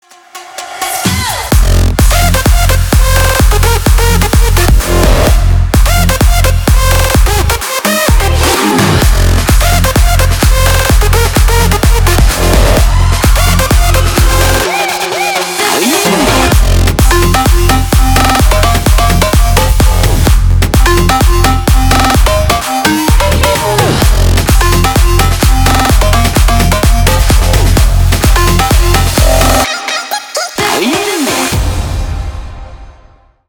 • Качество: 320 kbps, Stereo
Электроника
без слов